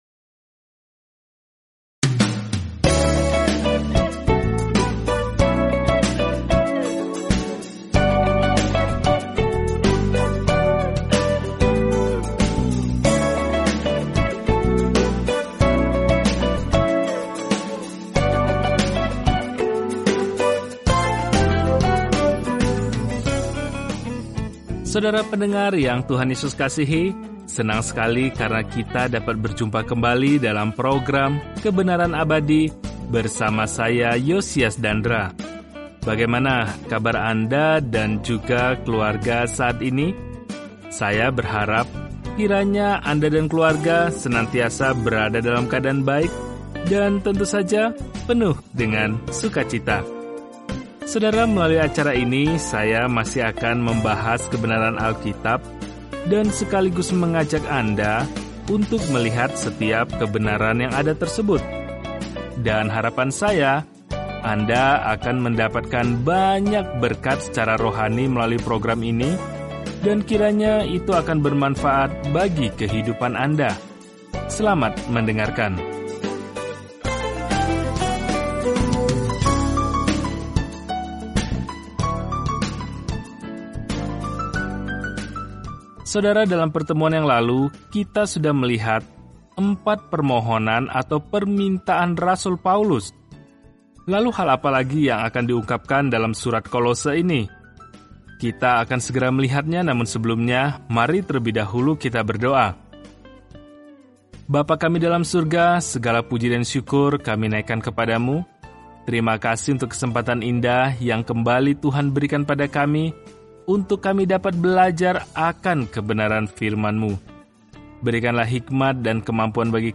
Jelajahi Kolose setiap hari sambil mendengarkan pelajaran audio dan membaca ayat-ayat tertentu dari firman Tuhan.